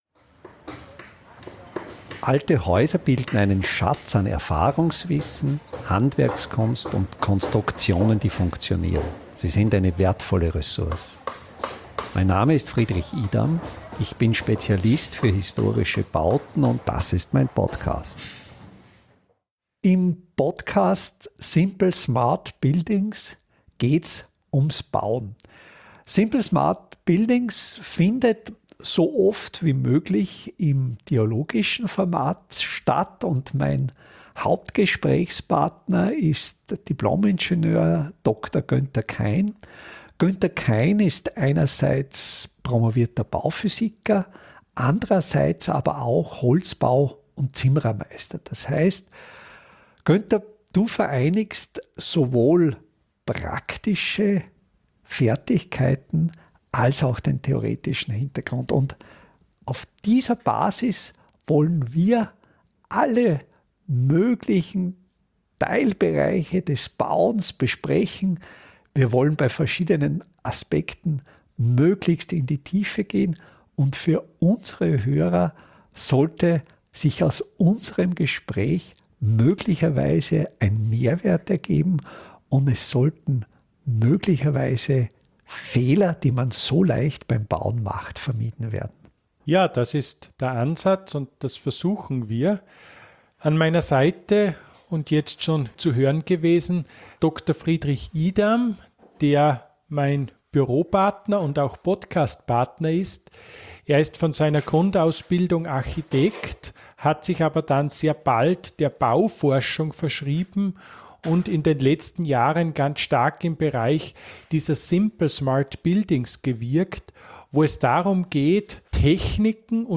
Gespräch über das Umbauen 02